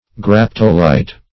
Search Result for " graptolite" : The Collaborative International Dictionary of English v.0.48: Graptolite \Grap"to*lite\, n. [NL, Graptolithus, from Gr.